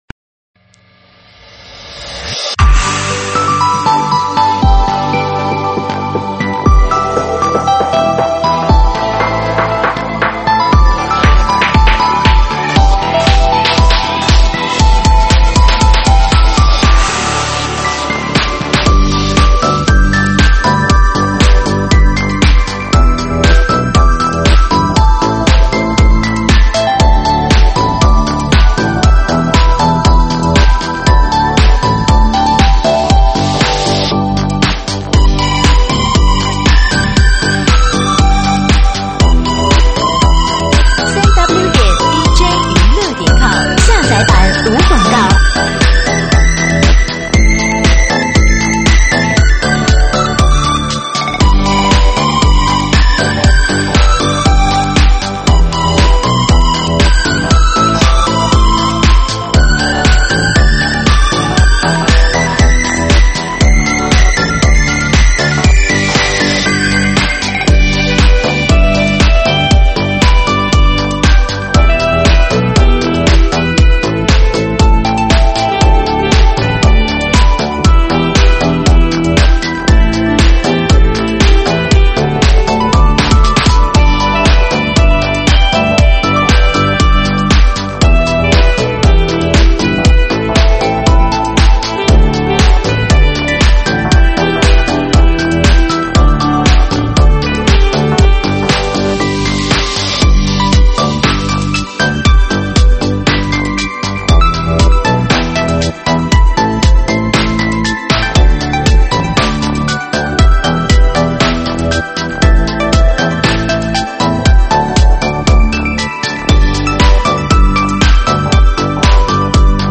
慢摇舞曲